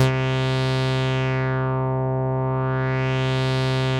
Index of /90_sSampleCDs/Trance_Explosion_Vol1/Instrument Multi-samples/Wasp Dark Lead
C3_wasp_dark_lead.wav